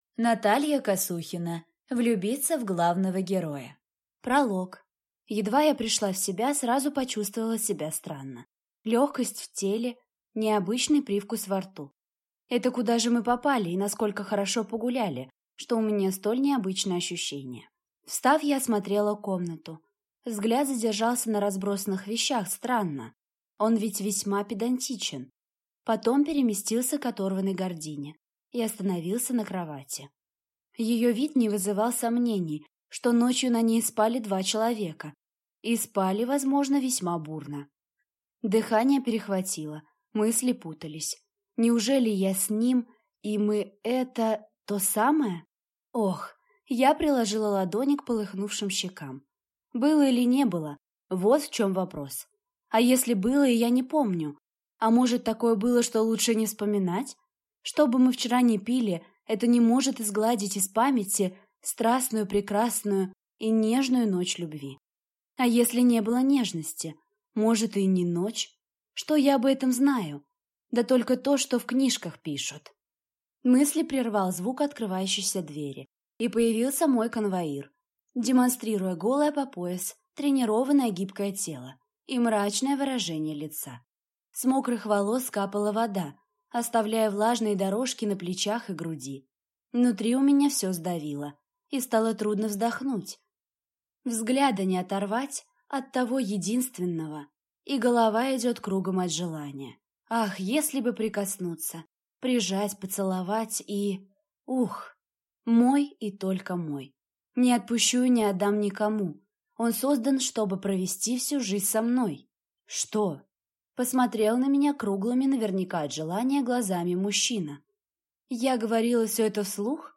Аудиокнига Влюбиться в главного героя | Библиотека аудиокниг